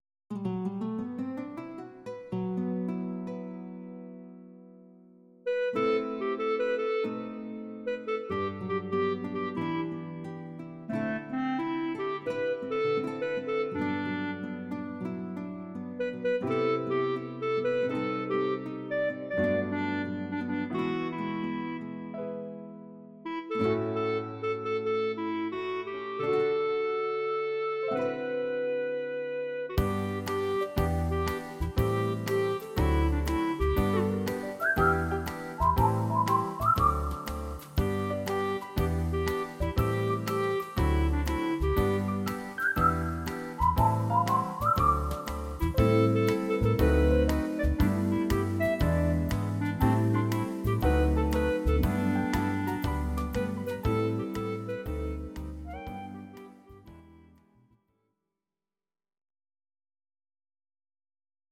Audio Recordings based on Midi-files
Pop, Musical/Film/TV, 1970s